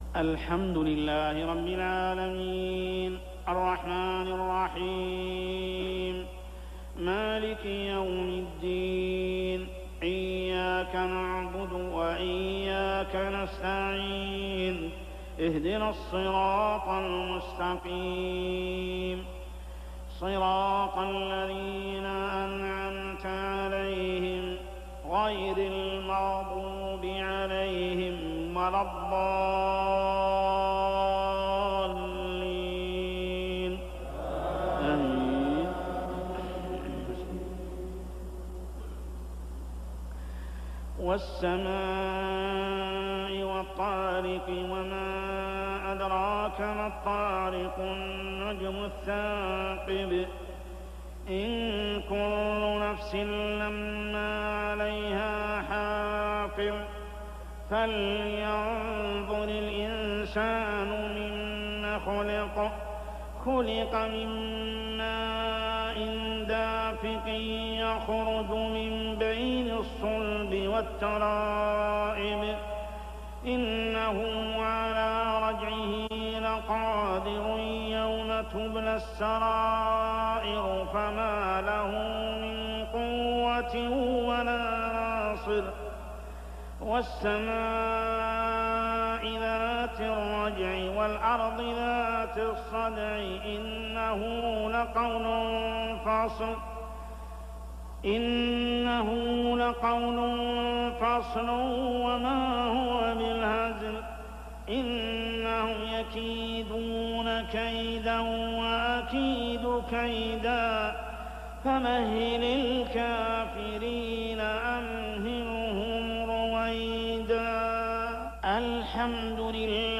صلاة العشاء 8-9-1413هـ سورتي الطارق و التين كاملة | Isha prayer Surah At-Tariq and At-Tin > 1413 🕋 > الفروض - تلاوات الحرمين